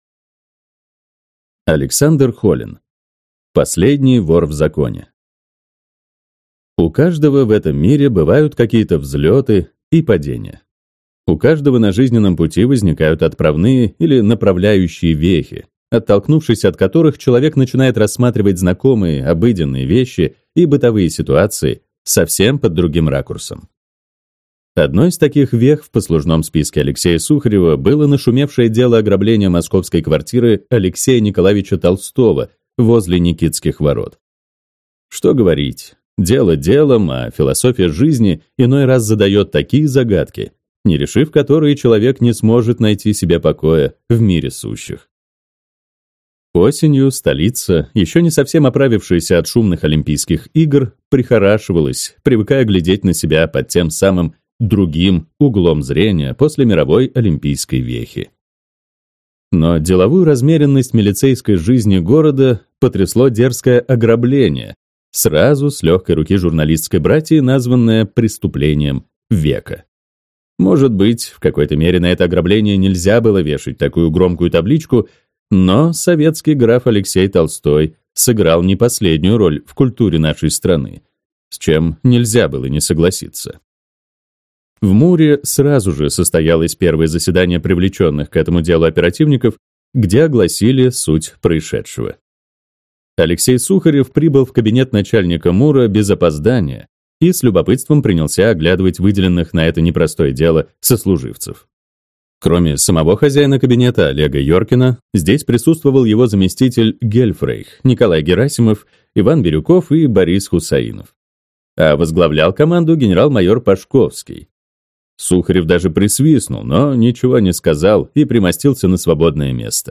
Аудиокнига Последний вор в законе | Библиотека аудиокниг